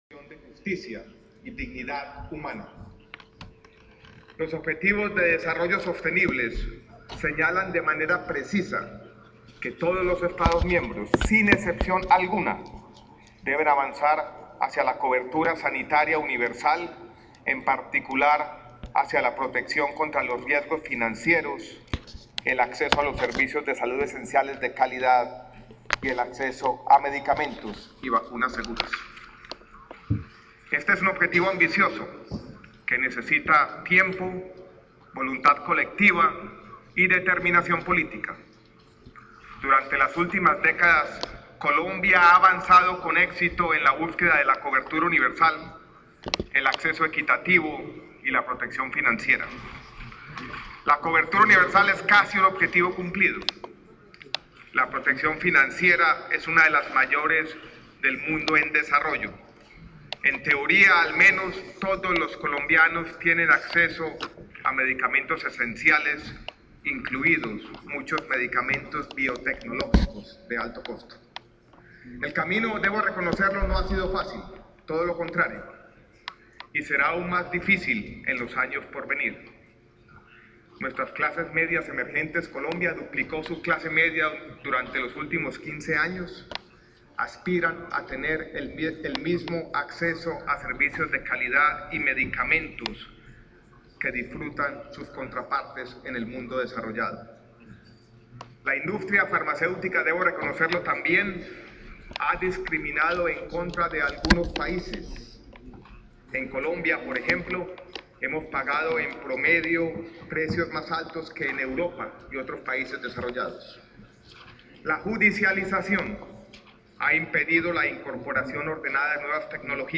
Audio: Palabras pronunciadas el por el Ministro de Salud y Protección Social,  Alejandro Gaviria Uribe en la 69a Asamblea Mundial de la Salud, que se celebra en Ginebra (Suiza).